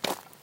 step3.wav